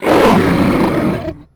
horror
Dragon Hurt Roar 6